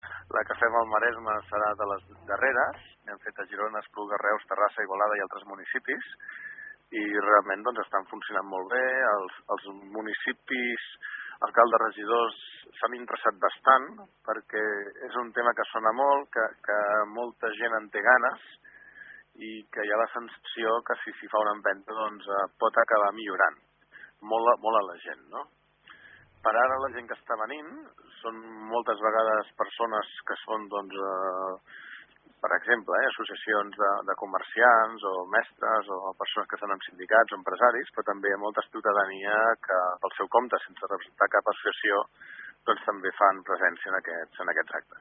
El Director del programa d’Innovació i Qualitat Democràtica de la Generalitat, Roger Buch, explica que els Ajuntaments on s’ha fet fins ara en fan una valoració positiva i apunta també a la diversitat professional de les persones que hi assisteixen.